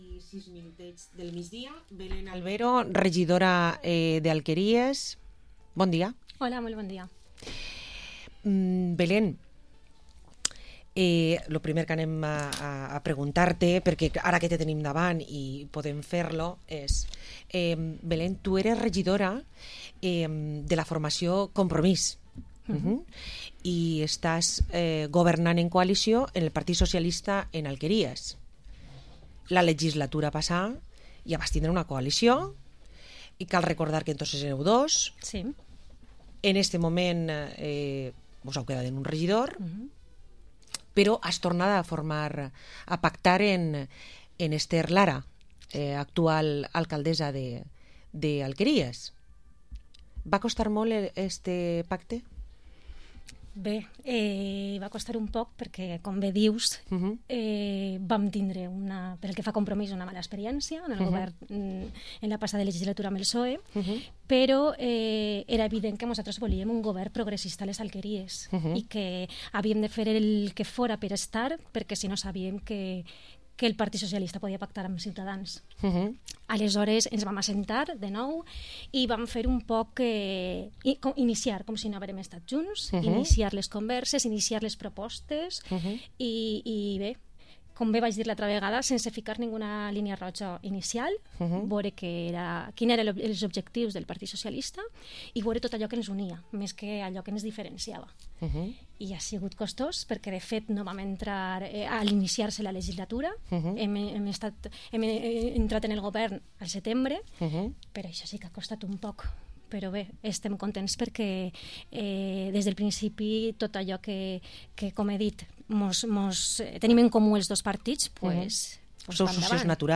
Entrevista Betlem Albero, edil de Compromís en les Alqueries